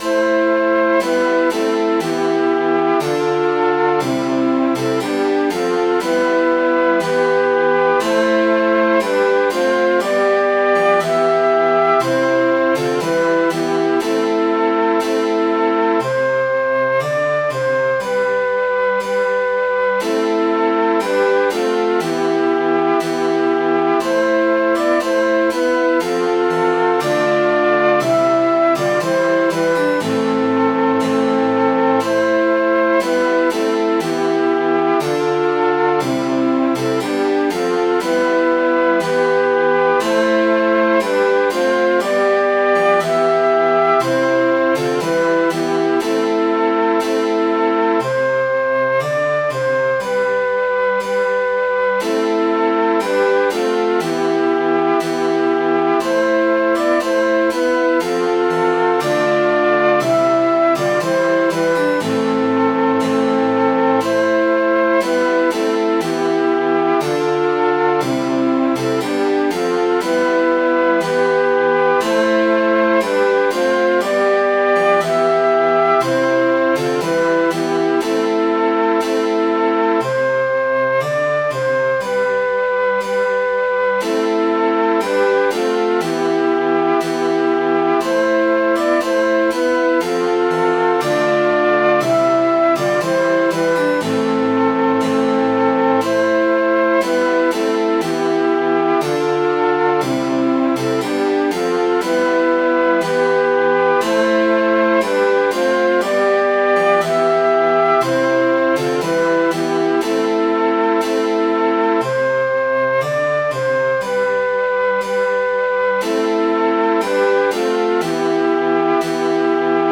Midi File, Lyrics and Information to Bunker Hillr